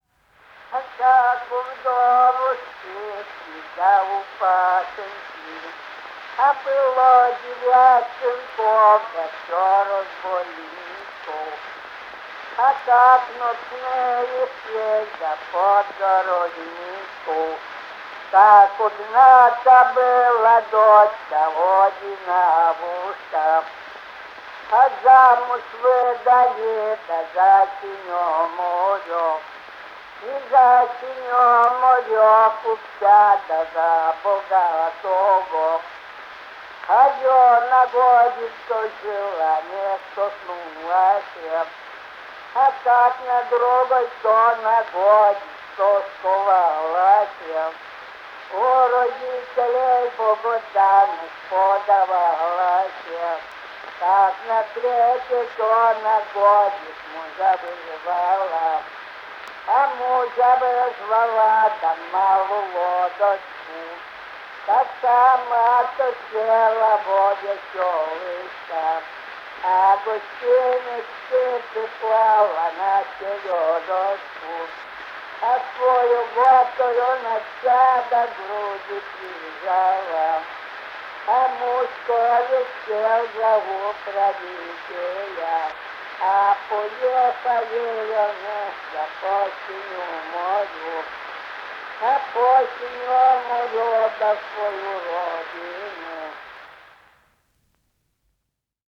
Биографические данные севернорусских сказителей и фольклорные тексты, записанные в фольклорно-этнографических экспедициях на Русский Север
Потомственный сказитель. Исполнитель былин, духовных стихов